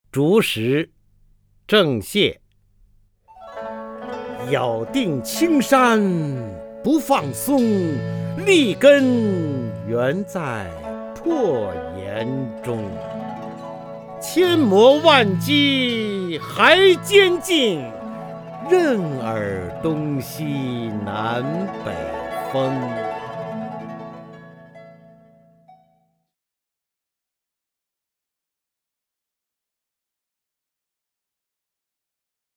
方明朗诵：《竹石》(（清）郑燮) （清）郑燮 名家朗诵欣赏方明 语文PLUS